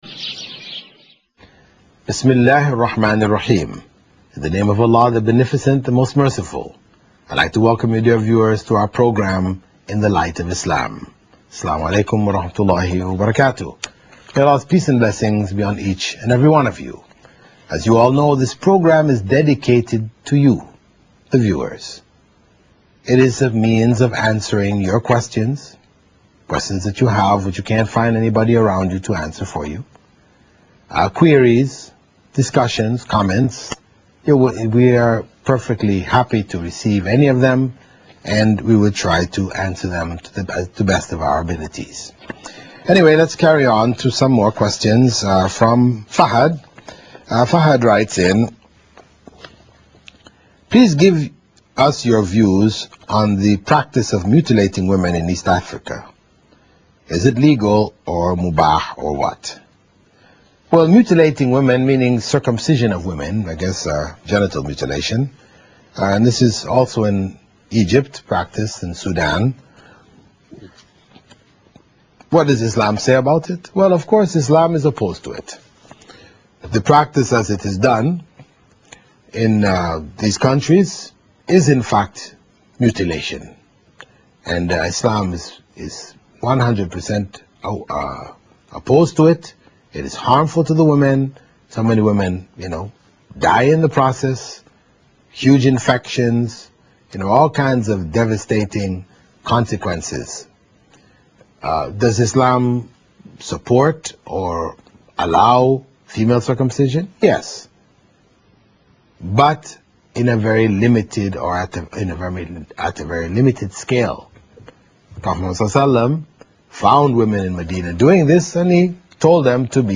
In the Light of Islam – Q&A